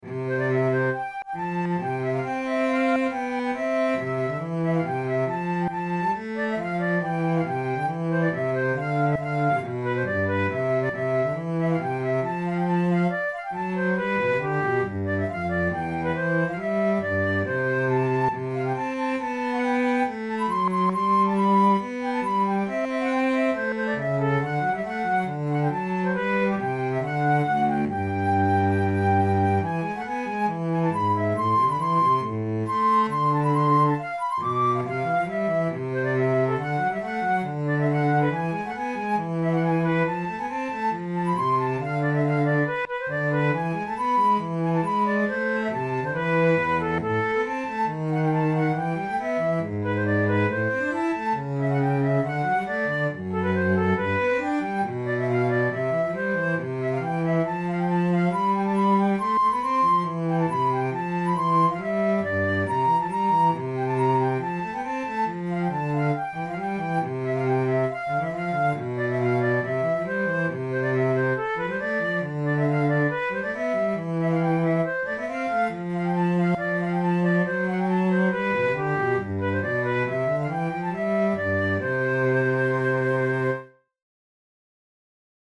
This joyous Allegro is the second movement of a Sonata in C major for recorder and basso continuo written by Italian composer Benedetto Marcello around 1712.
Categories: Baroque Sonatas Difficulty: intermediate